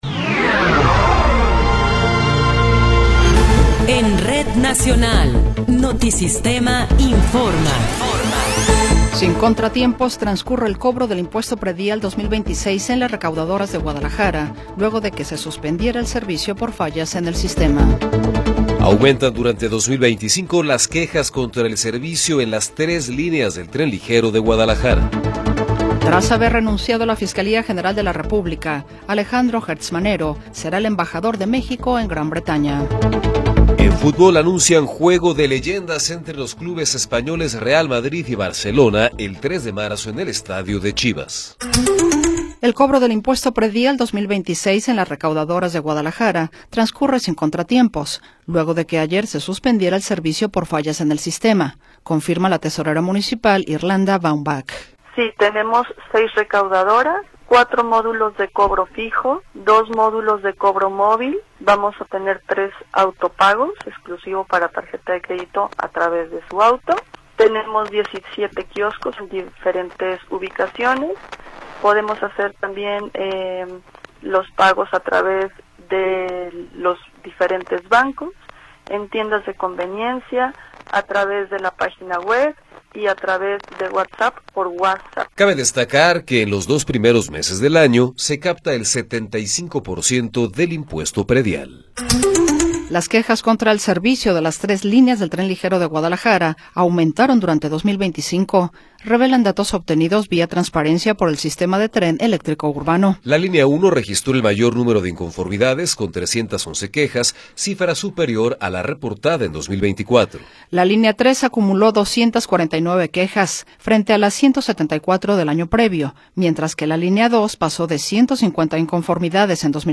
Noticiero 14 hrs. – 7 de Enero de 2026